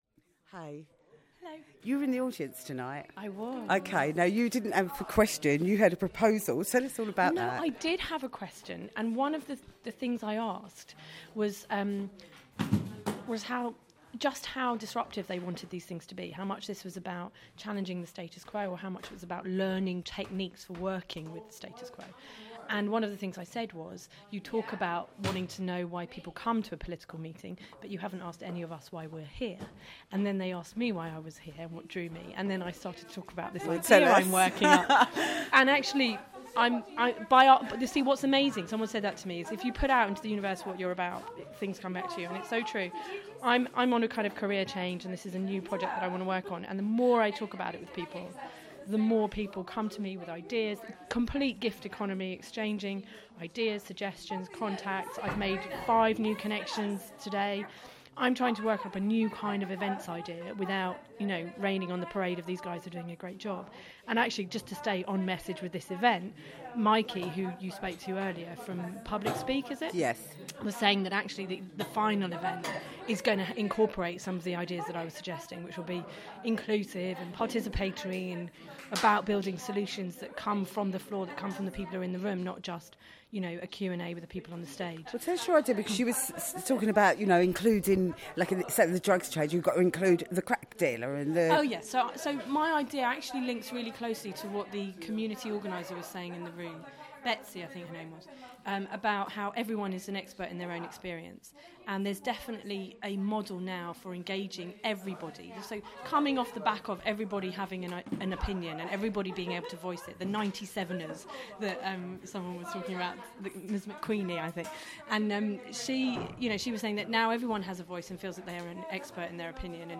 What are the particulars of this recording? From the Peckham Liberal Club.